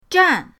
zhan4.mp3